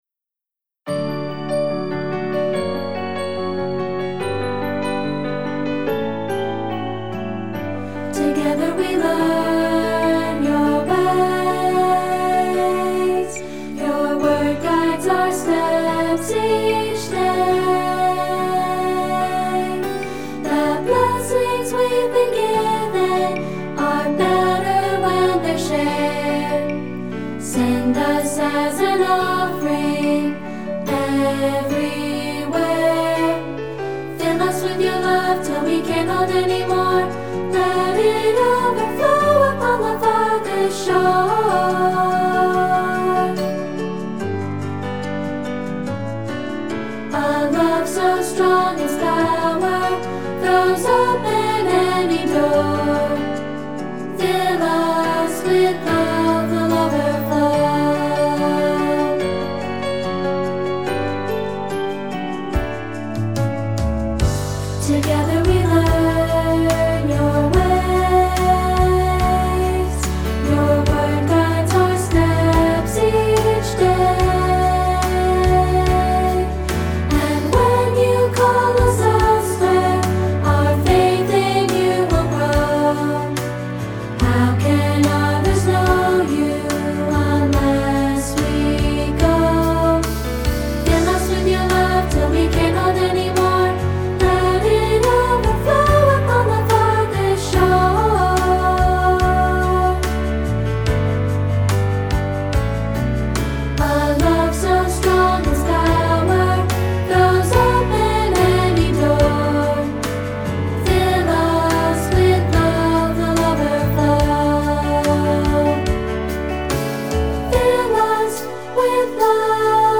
Voicing: Unison/2-Part